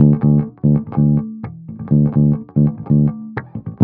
17 Bass Loop D.wav